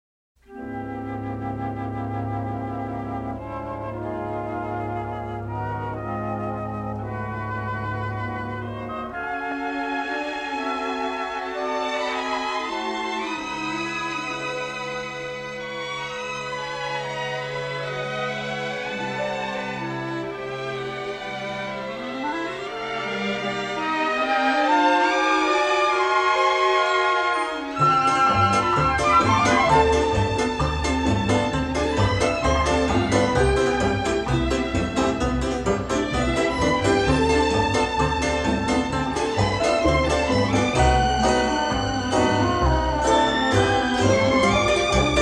a deeply melodic, romantic and sophisticated score